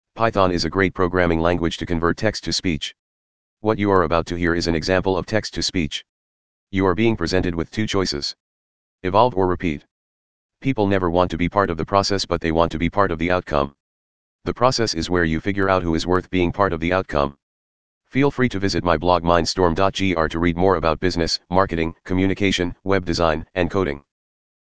Text to Speech with Python
Male voice – File created (mp3): welcome_4.wav